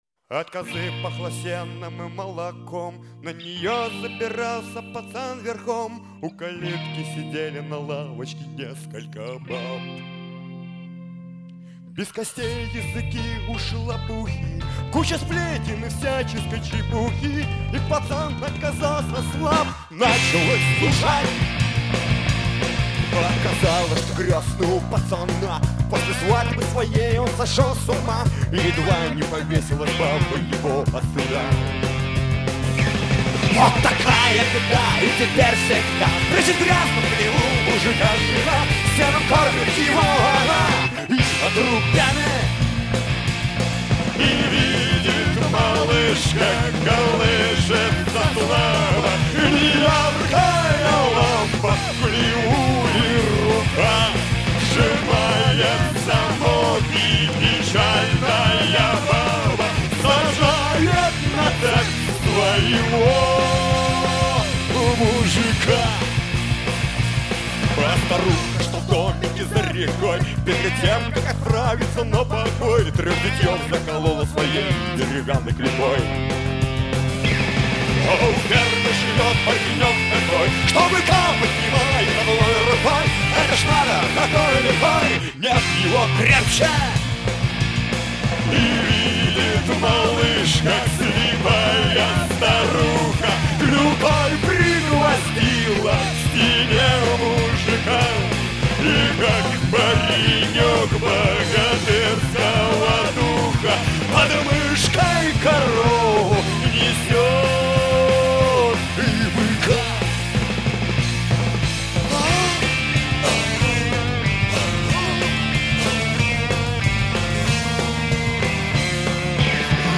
Категория: Рок